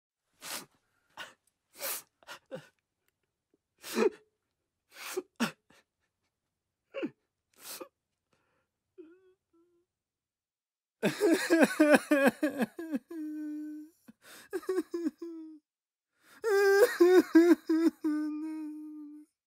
دانلود صدای گریه مرد 3 از ساعد نیوز با لینک مستقیم و کیفیت بالا
جلوه های صوتی